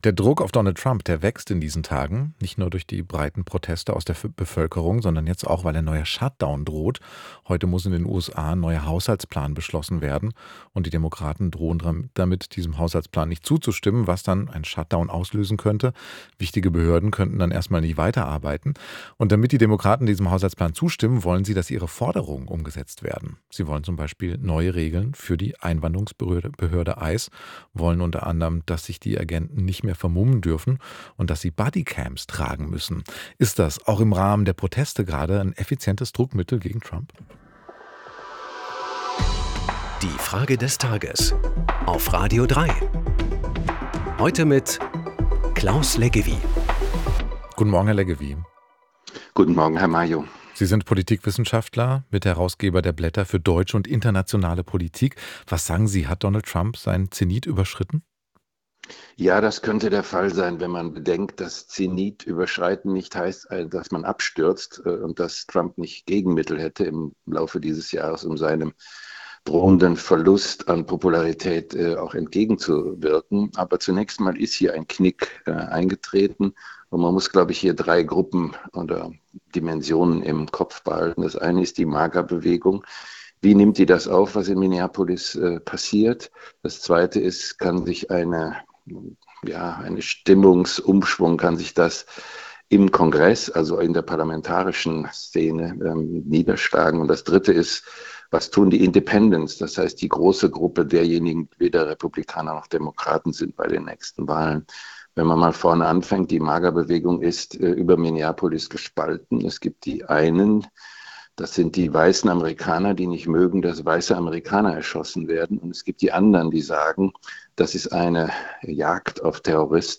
Zehn starke Stimmen im Wechsel "Die Frage des Tages" – montags bis freitags, immer um 8 Uhr 10.
Es antwortet der Politikwissenschaftler und